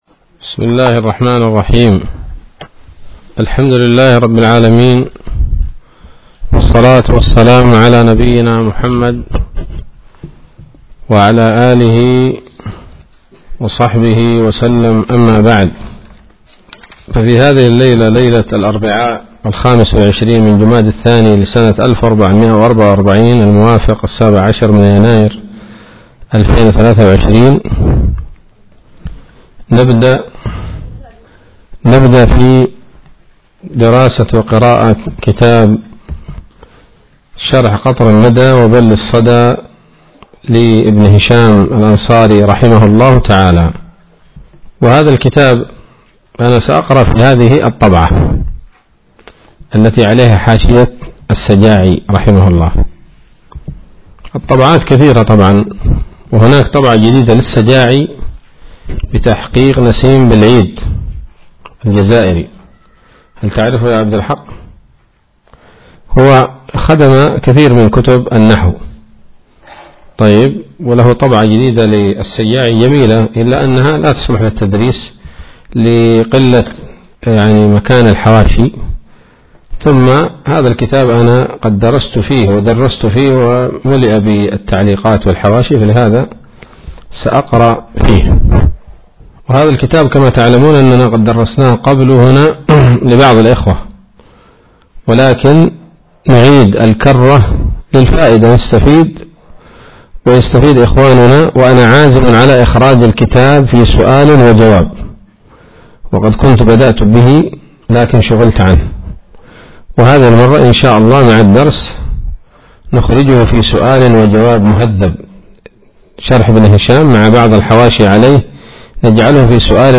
الدرس الأول من شرح قطر الندى وبل الصدى [1444هـ]
بدار الحديث السلفية بصلاح الدين حرسها الله